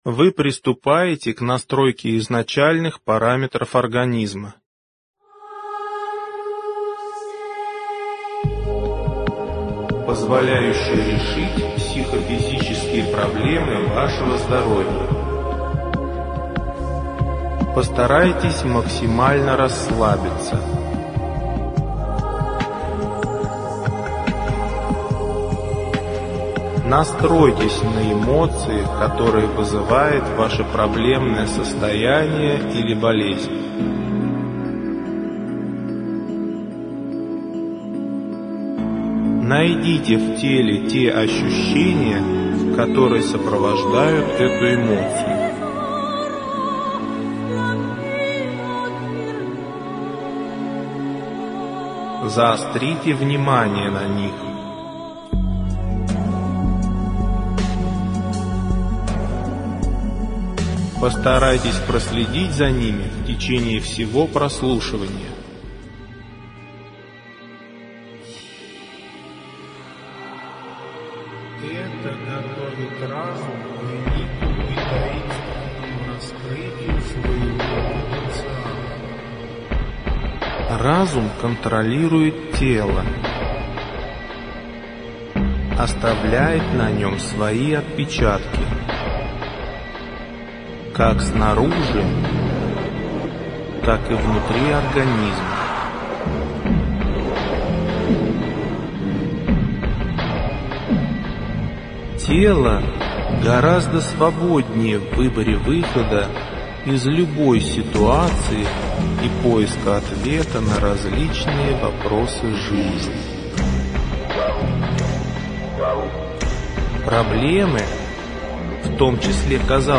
Аудиокнига Восстанавливающие психотехники. Диск 3 | Библиотека аудиокниг